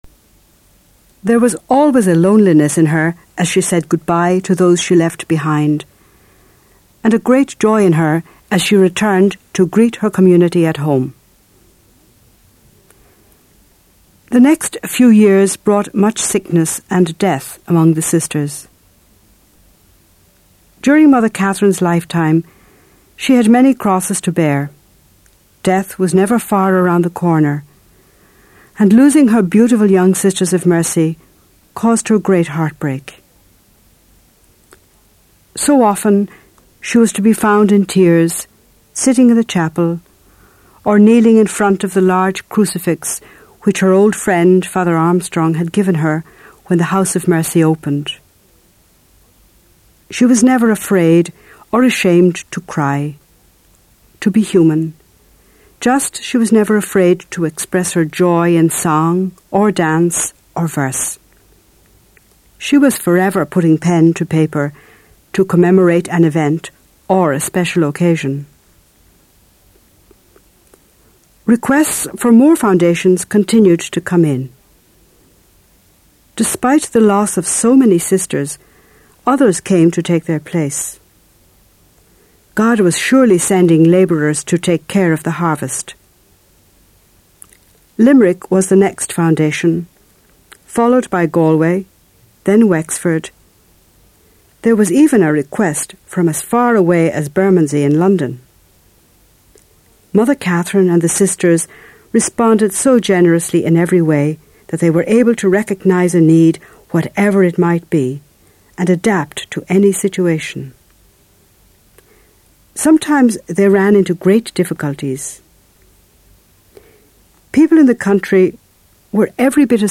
The story of Catherine McAuley for younger listeners